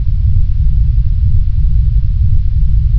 rumble-rough.wav